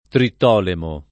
[ tritt 0 lemo ]